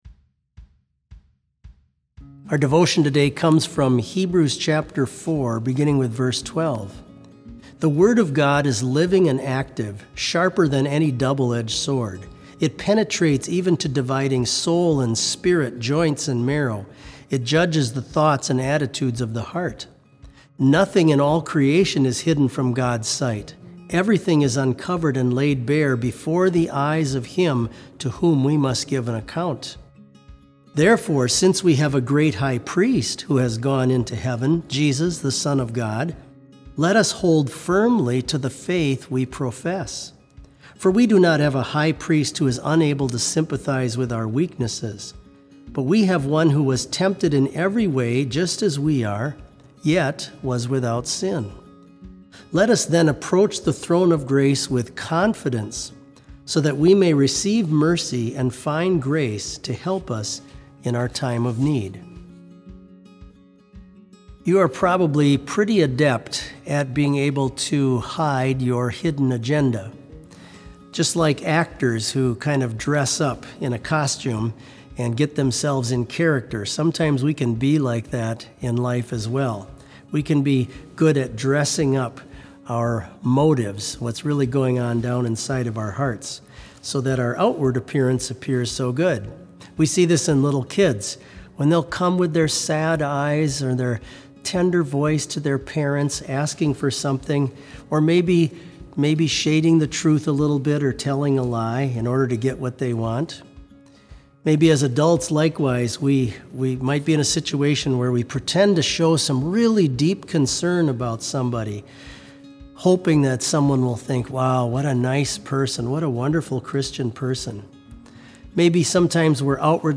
Complete service audio for BLC Devotion - April 7, 2020